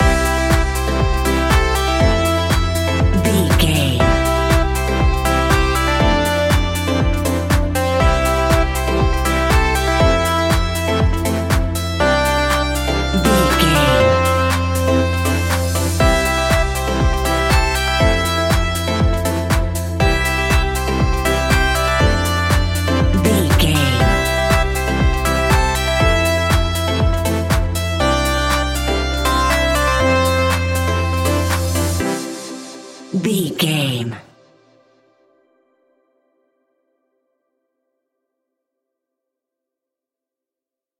Ionian/Major
groovy
dreamy
smooth
drum machine
synthesiser
funky house
deep house
nu disco
upbeat
funky guitar
fender rhodes
synth bass
horns